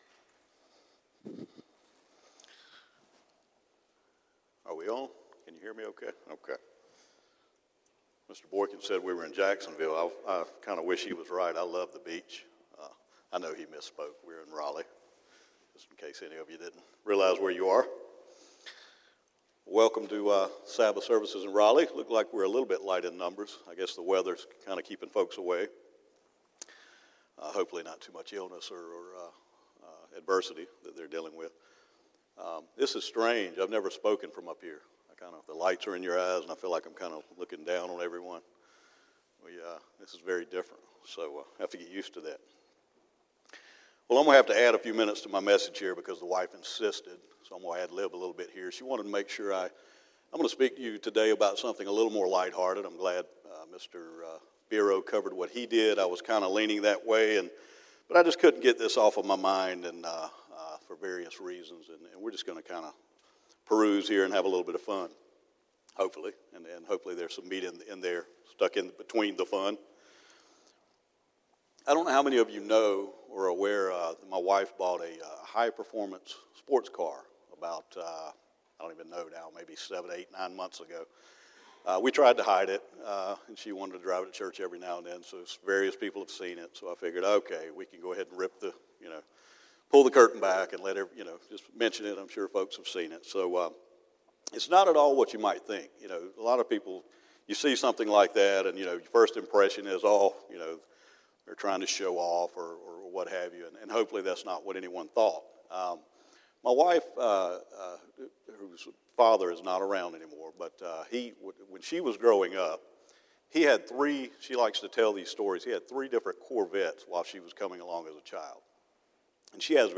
Given in Raleigh, NC
UCG Sermon Studying the bible?